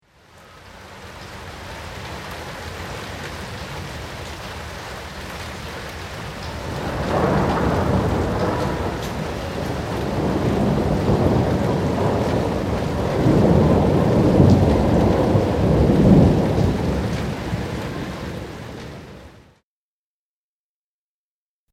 Ακούστε τη βροχή